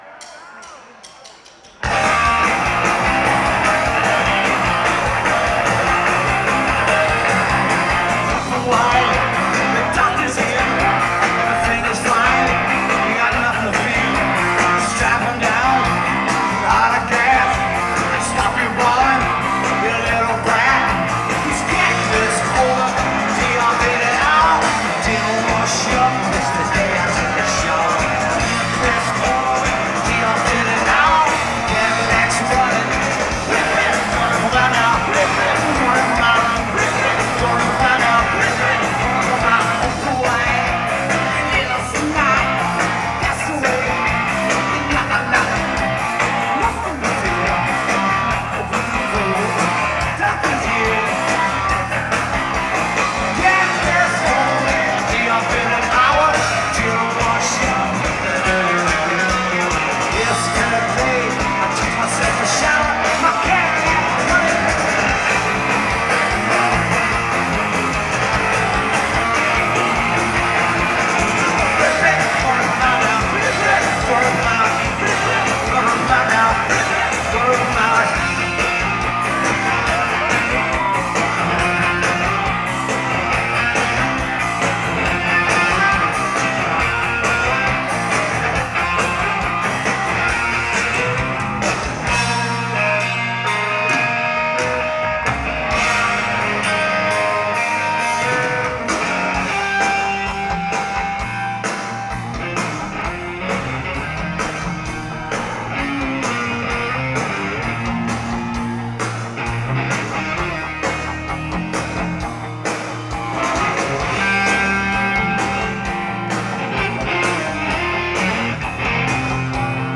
Live at Riot Fest
At Fort York, Toronto, Ontario, Canada